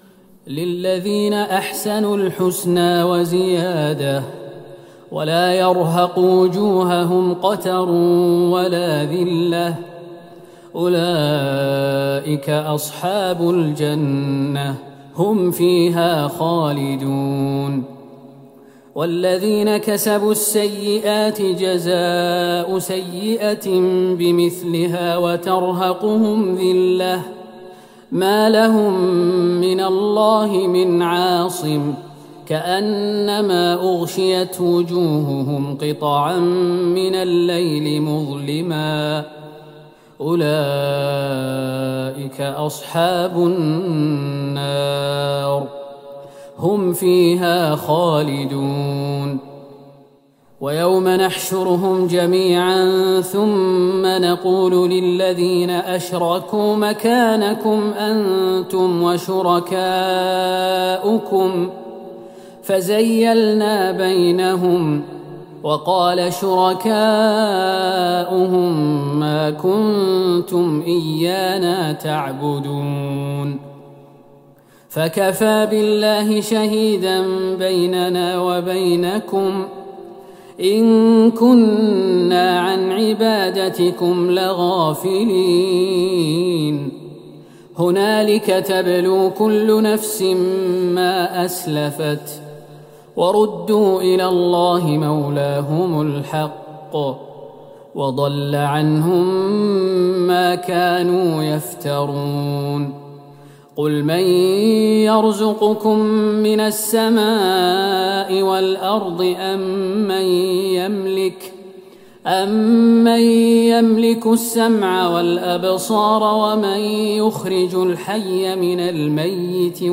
ليلة ١٥ رمضان ١٤٤١هـ من سورة يونس { ٢٦-٩٥ } > تراويح الحرم النبوي عام 1441 🕌 > التراويح - تلاوات الحرمين